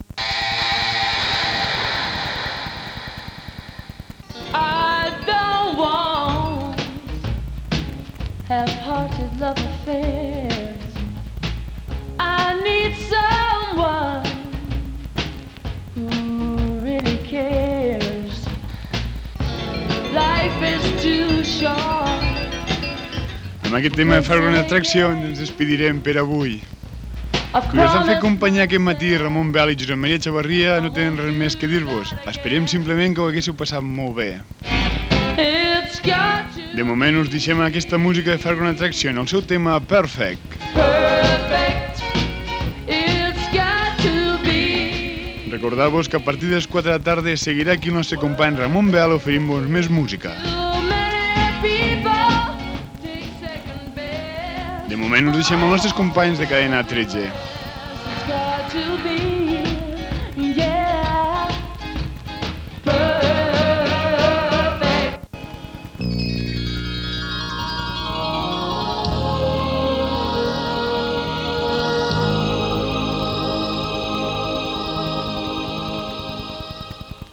113160f2e4635badddc241ae2aefc8f7c39cc027.mp3 Títol Cadena 13 Amposta Emissora Cadena 13 Amposta Cadena Cadena 13 Titularitat Privada nacional Descripció Comiat de l'espai musical i connexió amb Cadena 13. Gènere radiofònic Musical
Banda FM